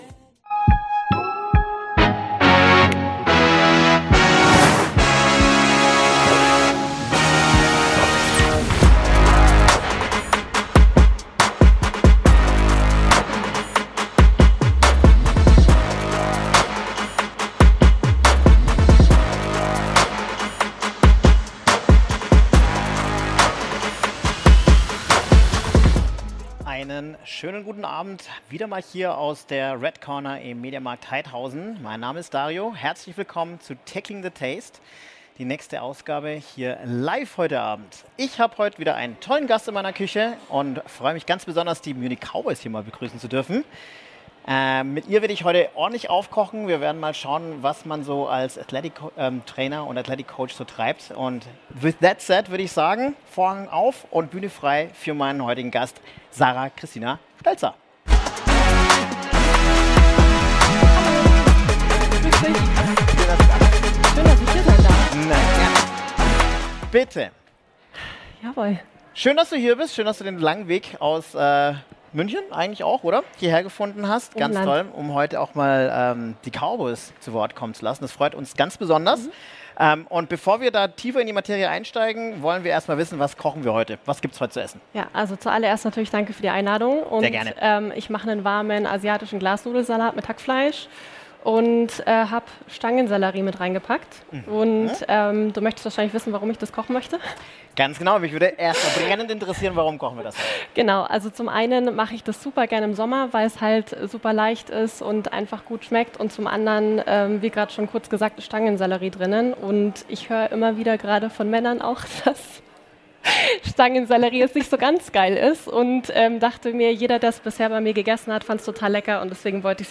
in unserer Küche zu Gast!